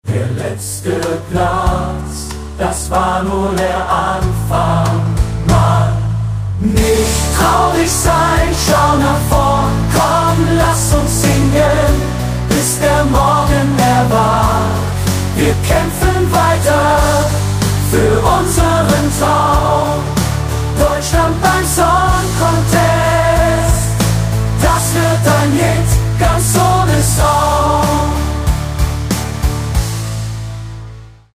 Sogar das Eingeben von Lyrics ist möglich, die die KI dann singt.